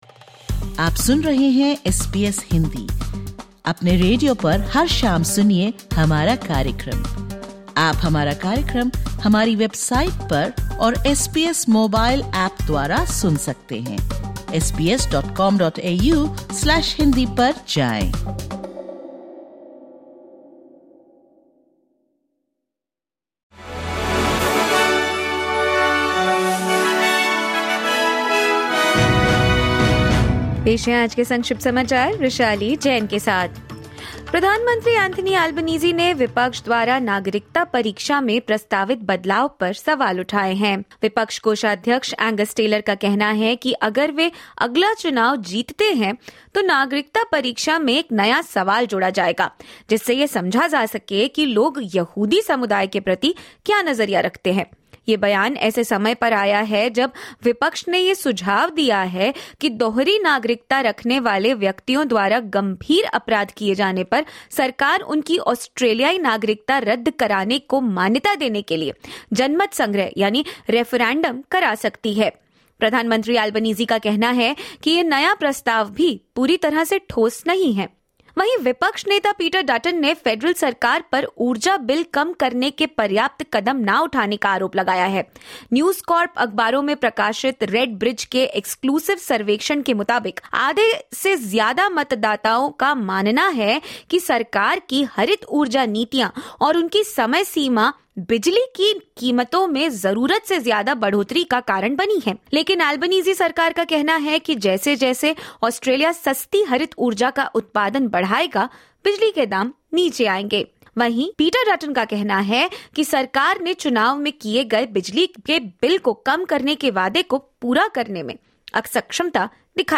Listen to the top News of 19/03/2025 from Australia in Hindi.